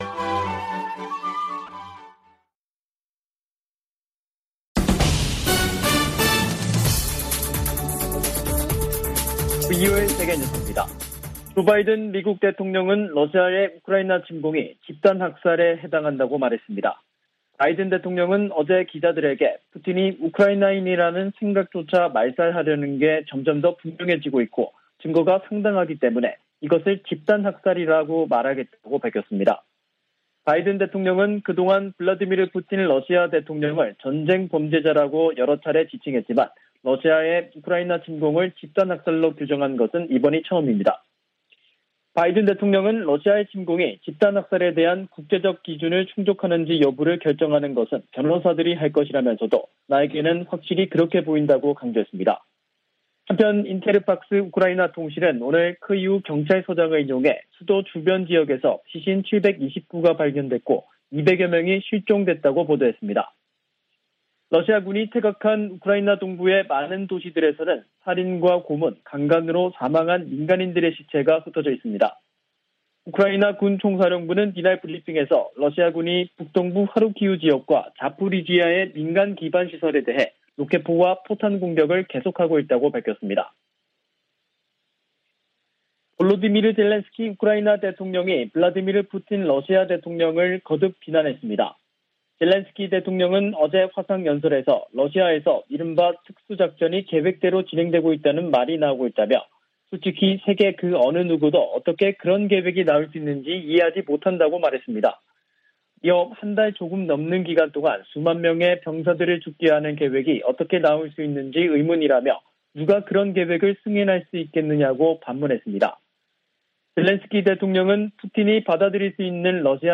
VOA 한국어 간판 뉴스 프로그램 '뉴스 투데이', 2022년 4월 13일 3부 방송입니다. 미 국무부는 연례 인권보고서에서 북한이 세계에서 가장 억압적이고 권위주의적인 국가라고 비판했습니다. 미 국방부는 북한이 전파 방해와 같은 반우주역량을 과시하고 있으며, 탄도미사일로 인공위성을 겨냥할 수도 있다고 평가했습니다. 한국 윤석열 차기 정부의 초대 외교부와 통일부 장관에 실세 정치인들이 기용됐습니다.